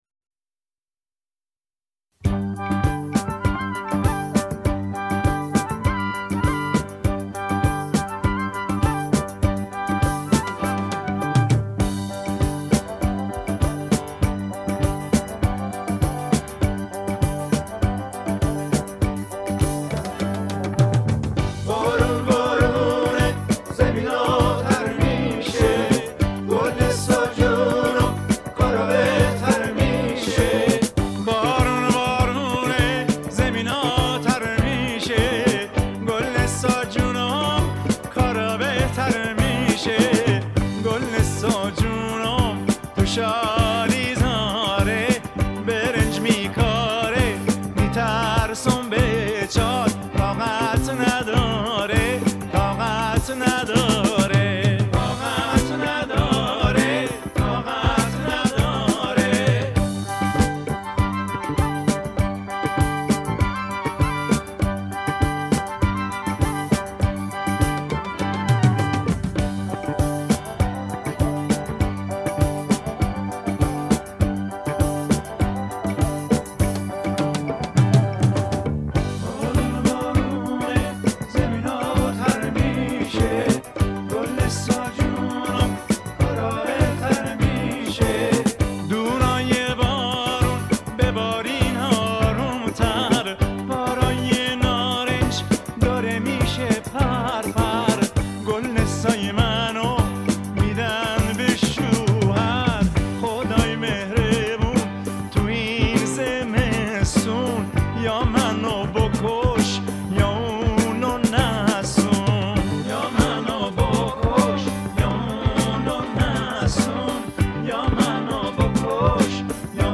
دانلود آهنگ شاد